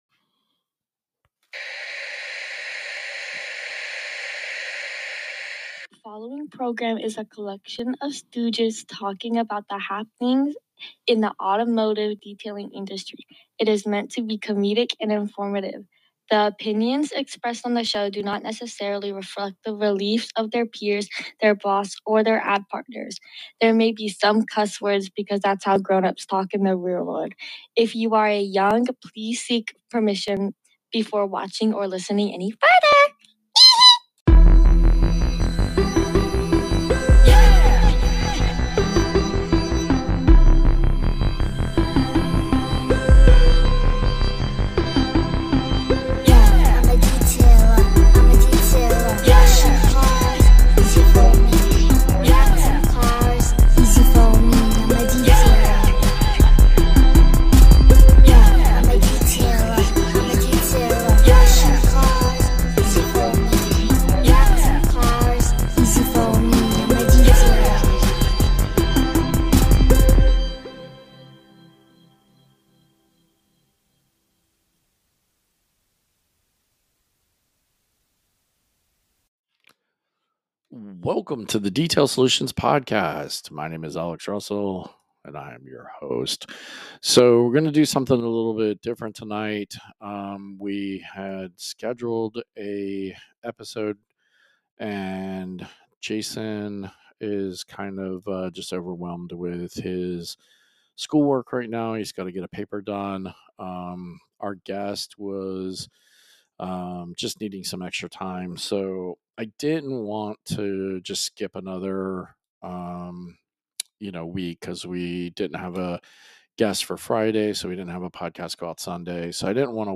Took the audio from when I went to Sky's the Limit Car Care in Sanford, Fl a couple weeks back to make this episode.
Towards the end I added some audio from the detailers that were on hand to play with the NSP's and their reactions to removing scratches up to 1500 grit.